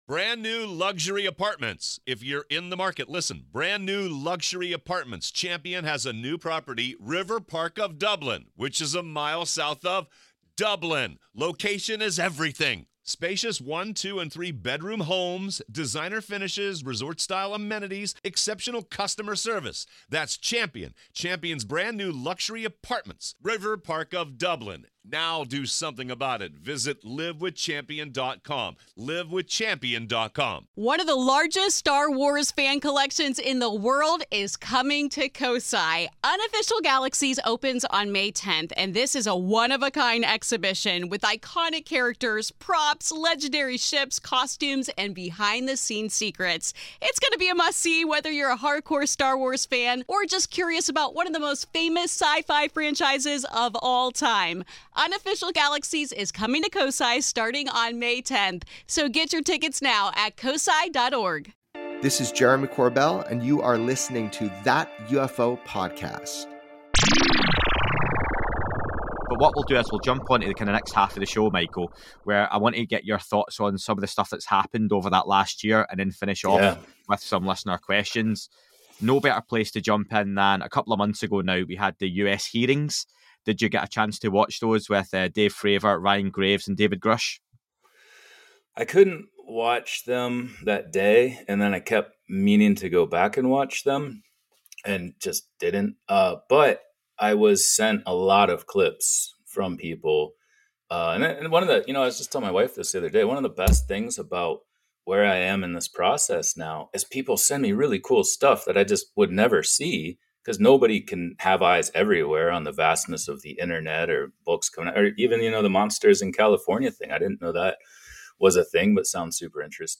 in this second of a two part interview discussing;